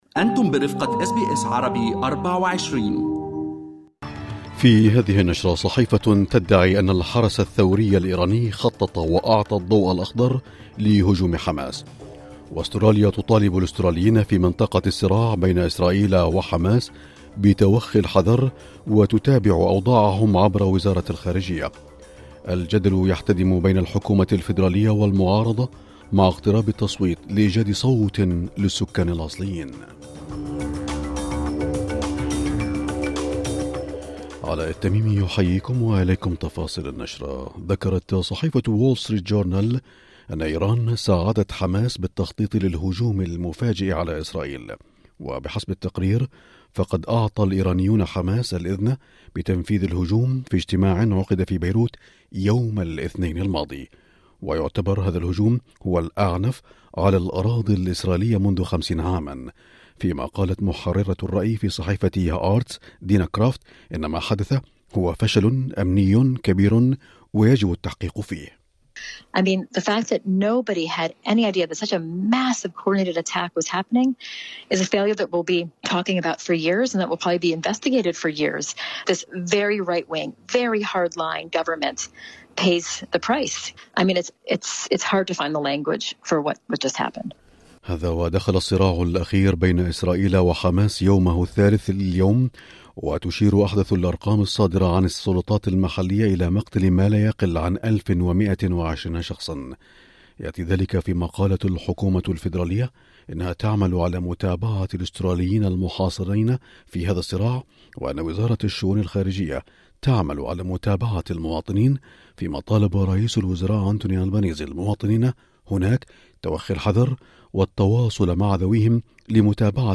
نشرة أخبار المساء9/10/2023